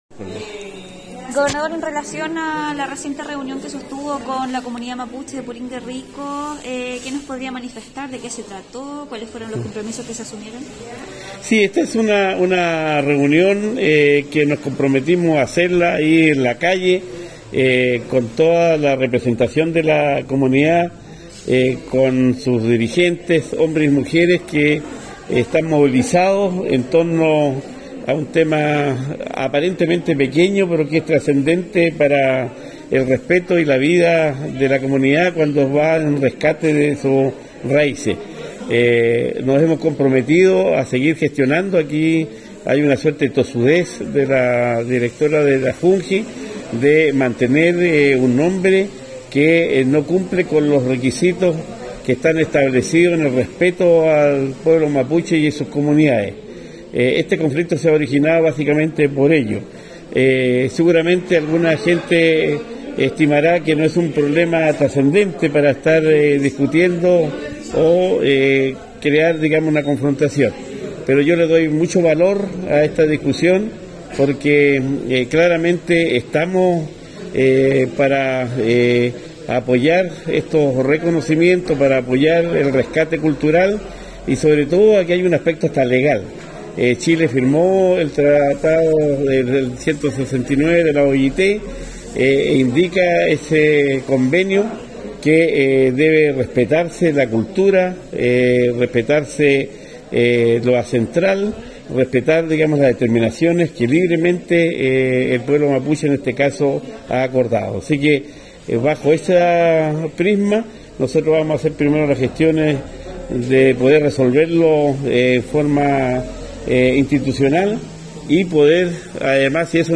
WhatsApp Audio 2021-09-25 at 16.51.20